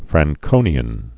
(frăng-kōnē-ən, -kōnyən, frăn-)